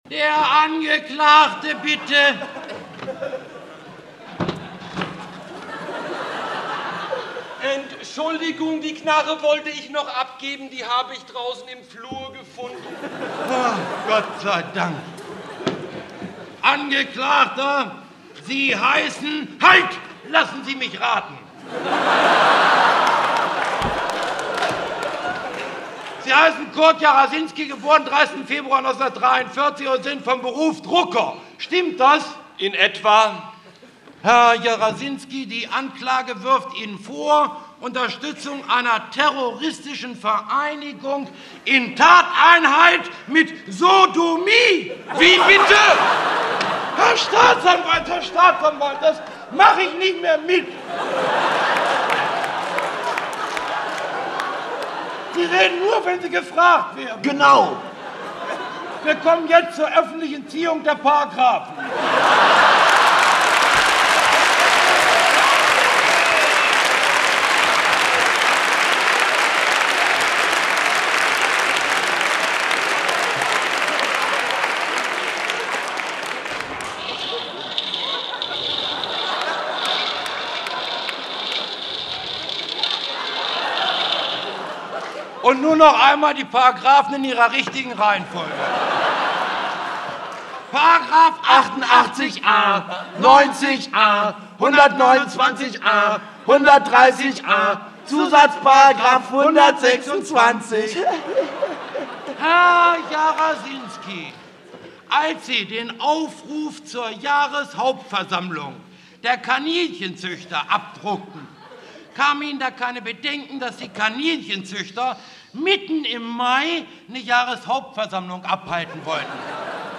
Live-Mitschnitt vom 17.06.1979 im Quartier Latin Berlin